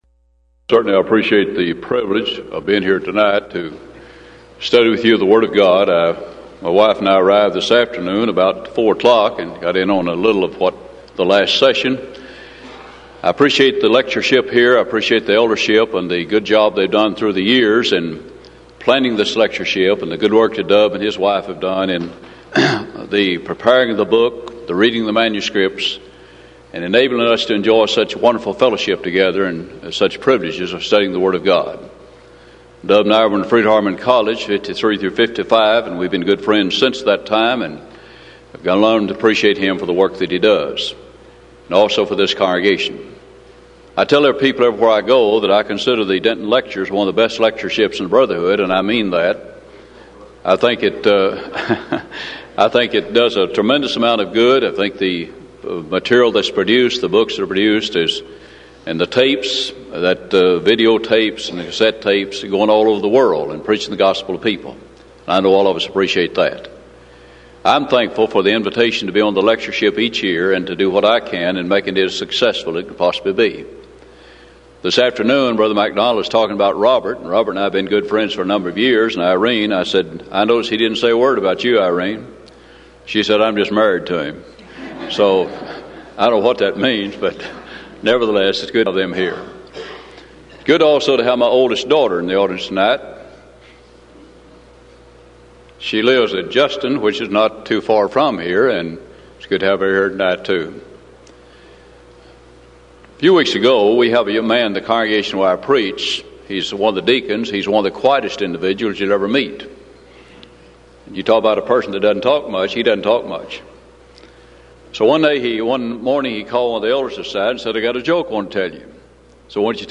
Event: 1994 Denton Lectures Theme/Title: Studies In Joshua, Judges And Ruth